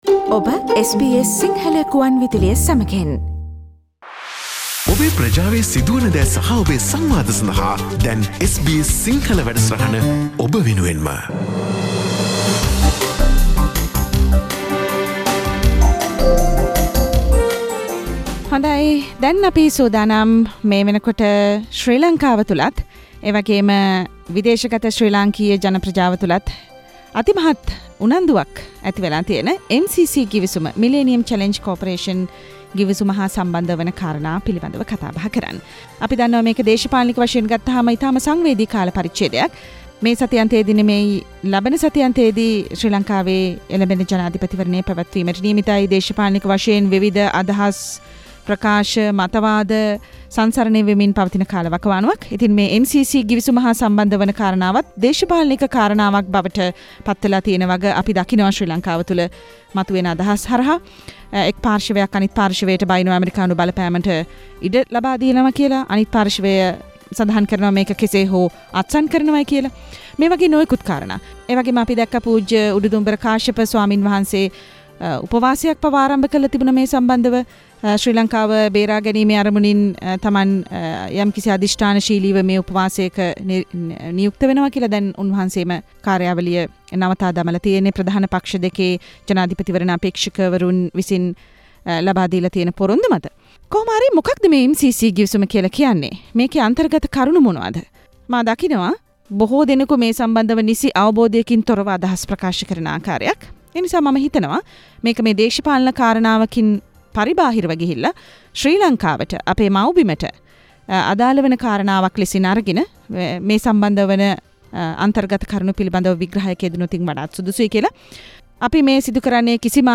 සාකච්චාව මෙම විශේෂාංගයෙන්